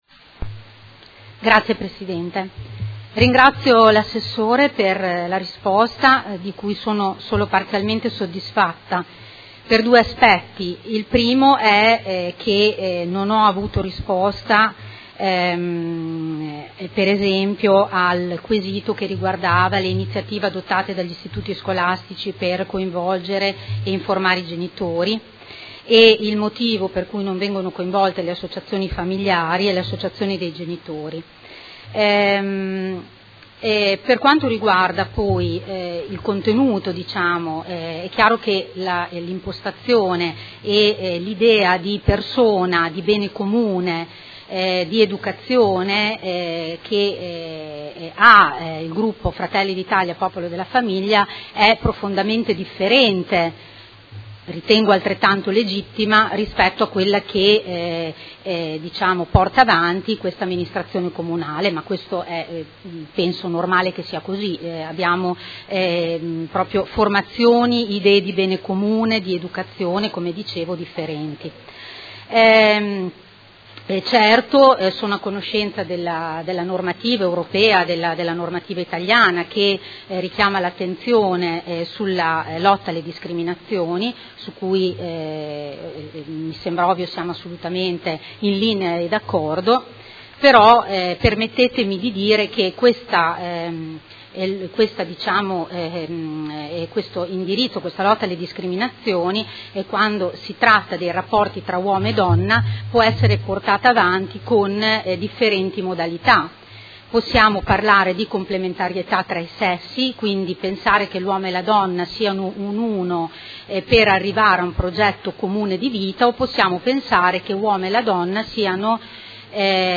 Elisa Rossini — Sito Audio Consiglio Comunale